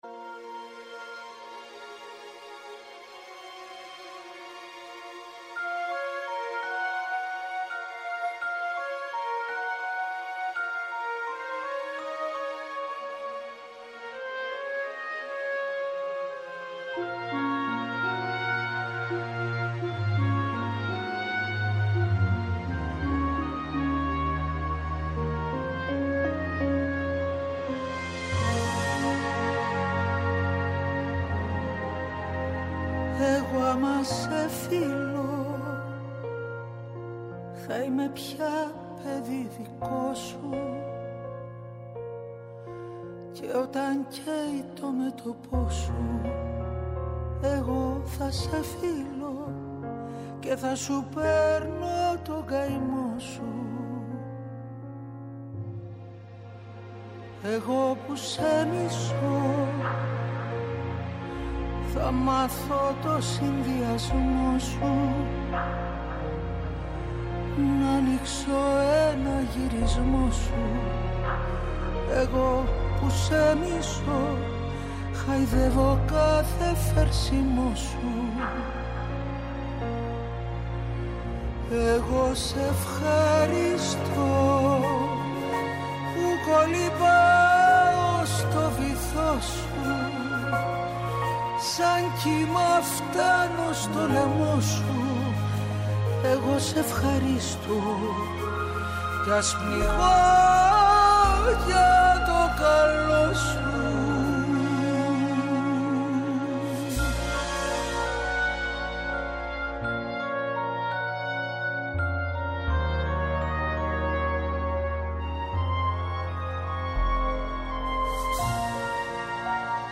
Καλεσμένη απόψε στο στούντιο